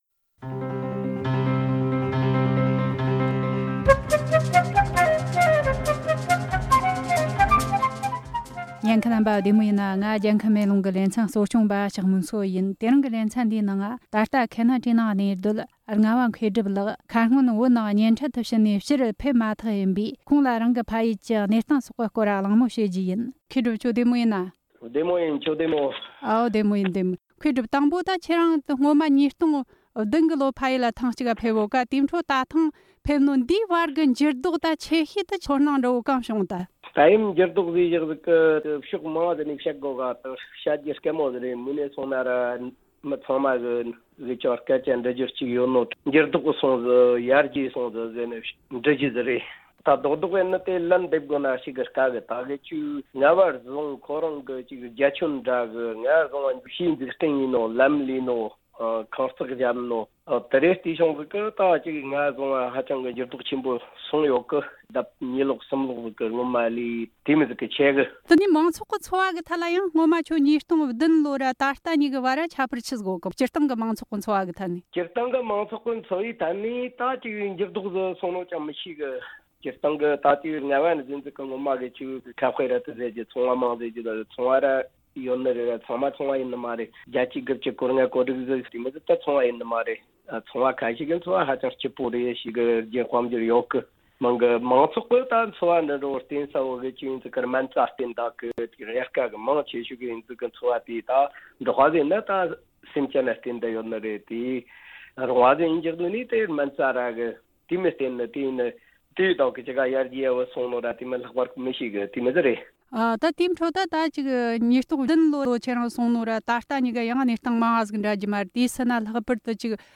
སྒྲ་ལྡན་གསར་འགྱུར། སྒྲ་ཕབ་ལེན།
གསར་འགོད་པ